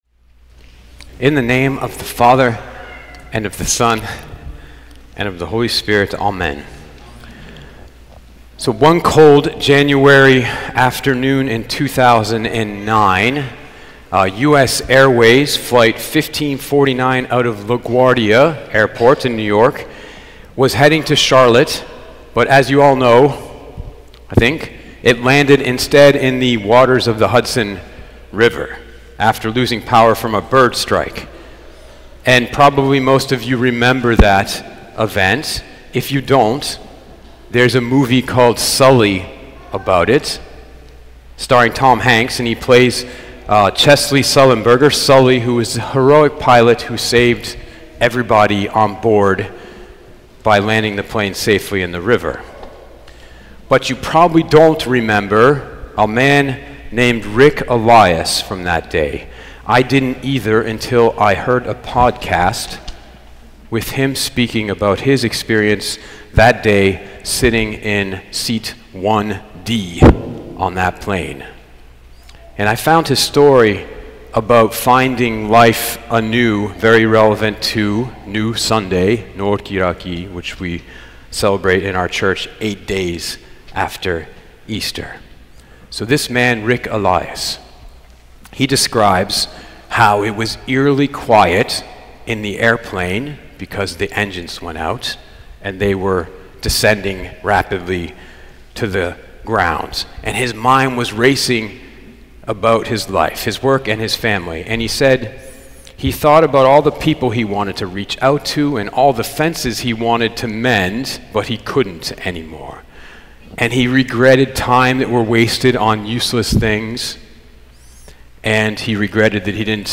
Podcasts Sermons St. Hagop Armenian Church